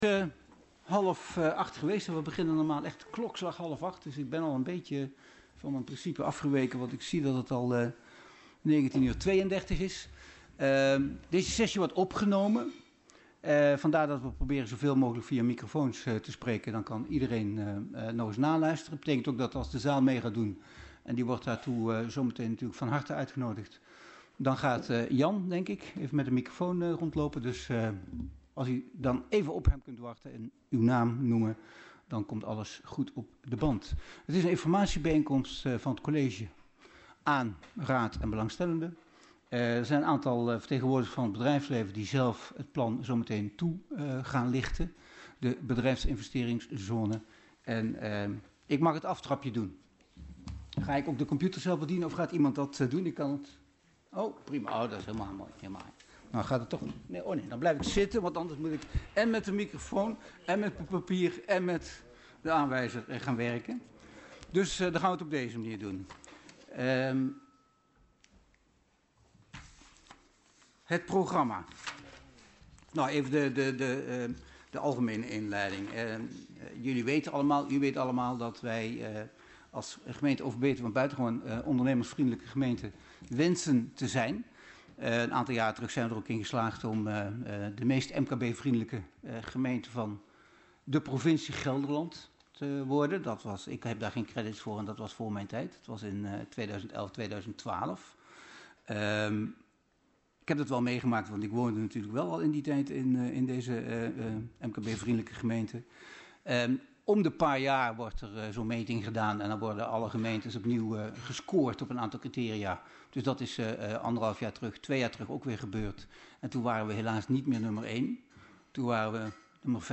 Locatie Hal, gemeentehuis Elst Voorzitter dhr. A.S.F. van Asseldonk Toelichting College informatiebijeenkomst over Bedrijveninvesteringszone (BIZ) De Aam Agenda documenten 16-09-27 Opname inzake College informatiebijeenkomst over Bedrijveninvesteringszone (BIZ) De Aam.MP3 30 MB